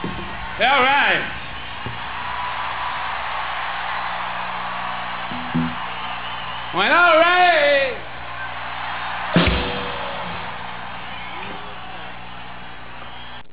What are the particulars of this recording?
Live Audio Clips Foxboro Sep. 5th